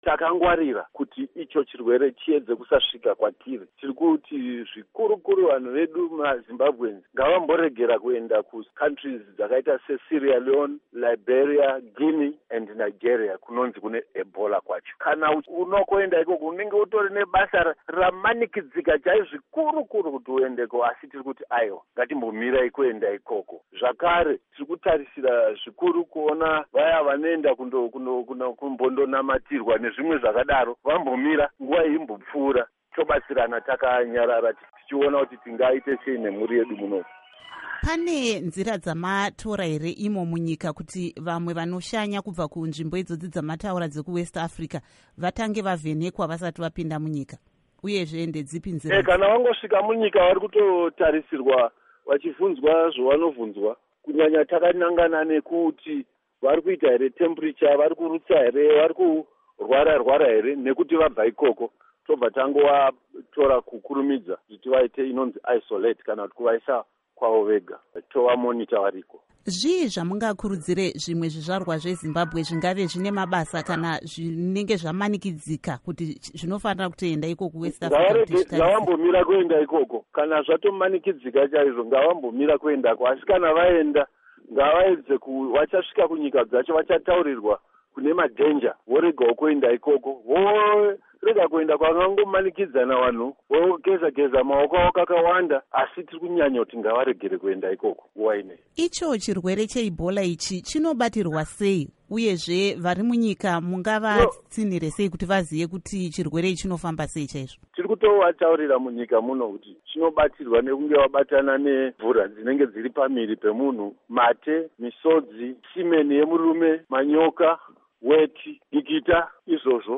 Hurukuro NaDoctor David Parirenyatwa